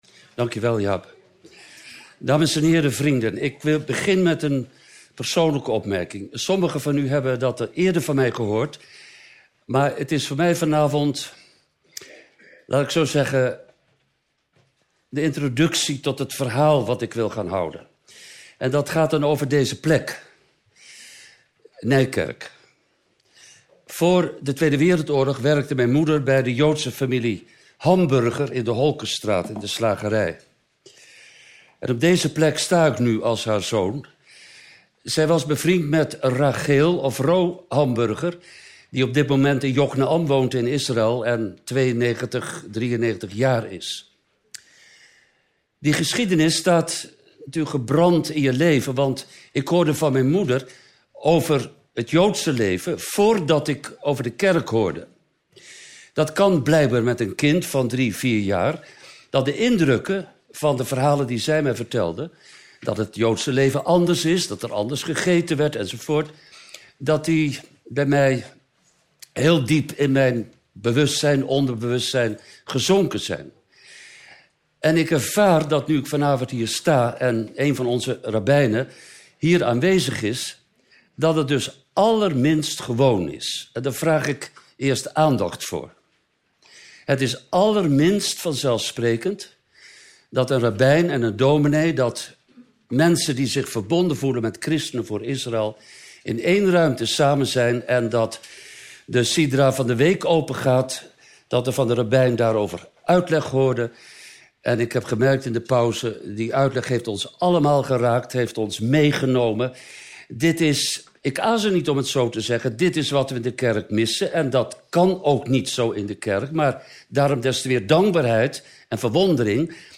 Tegelijkertijd ontdekte hij hoe de God van Israël ons heeft gezocht en hoe Israël een instrument is om juist ons in ons heidendom te bereiken. Daarover meer in deze prachtige lezing.